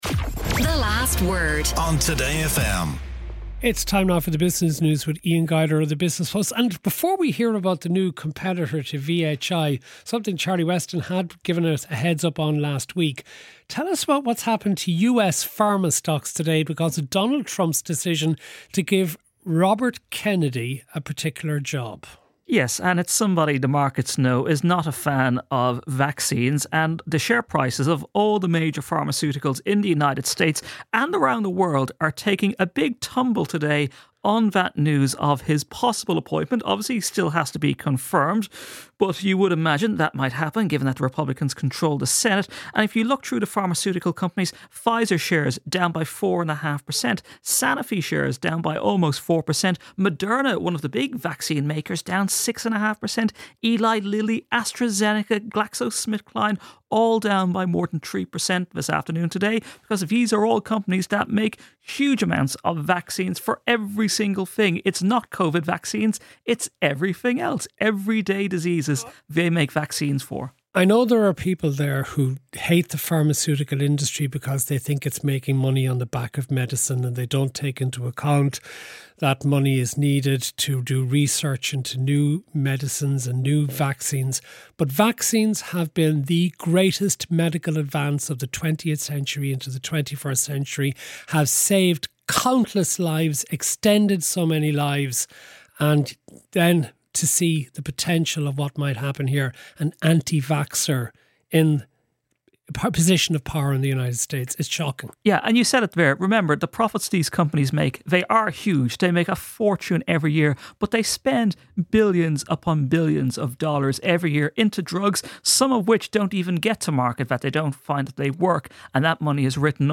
The move is expected to boost competition within the market. Hit the ‘Play’ button on this page to hear the conversation.